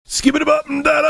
click-close.mp3